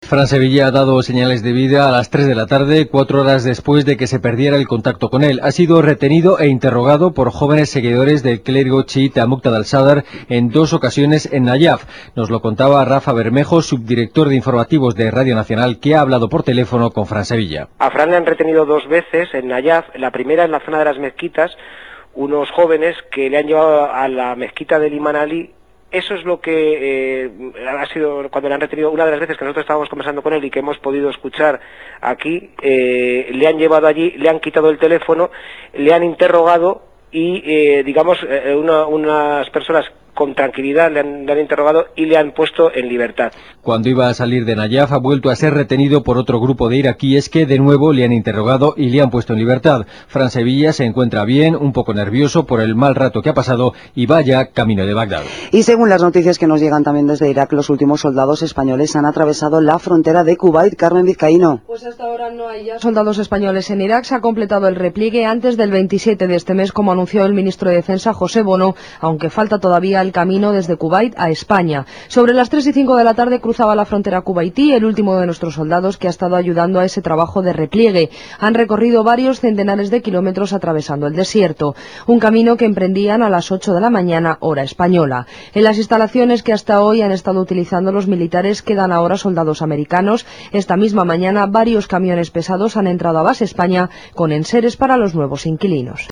Per començar, escoltarem tres fragments de diferents butlletins de notícies de ràdio. Corresponen al dia 21 de maig de 2004 i fan referència a la retirada de les tropes espanyoles de l’Iraq: